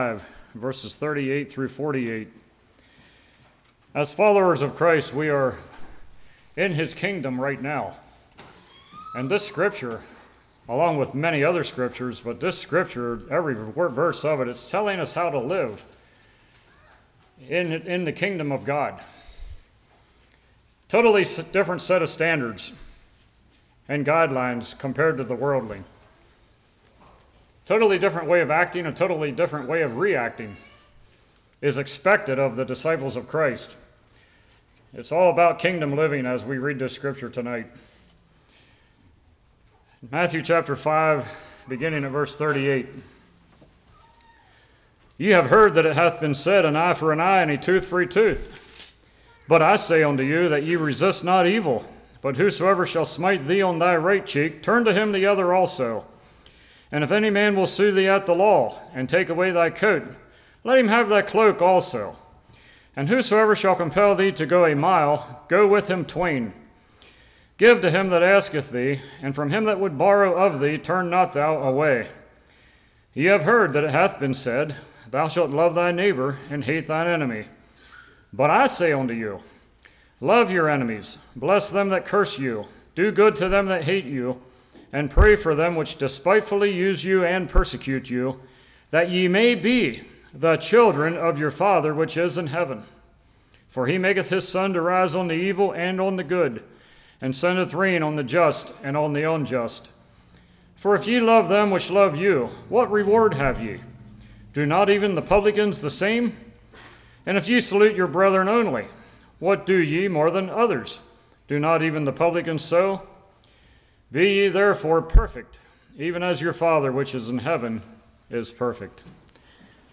Passage: Matthew 5:38-48 Service Type: Revival